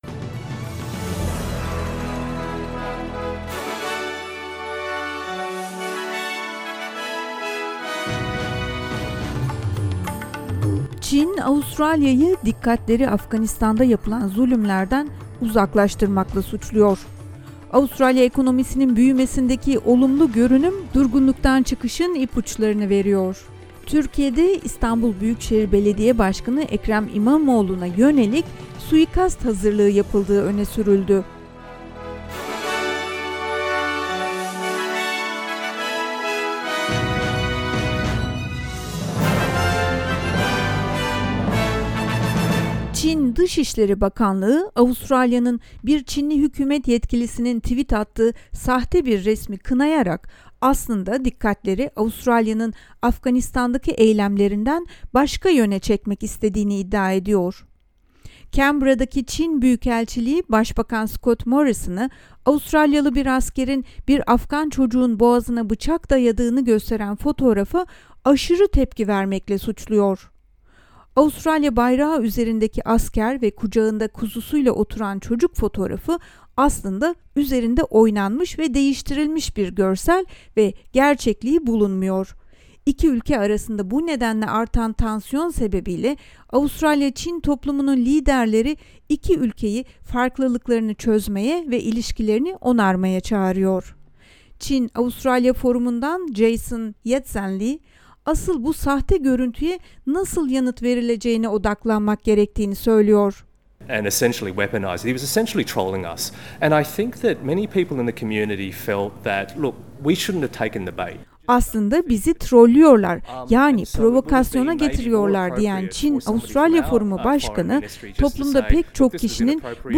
SBS Türkçe Haberler 2 Aralık
2_aralik_news.mp3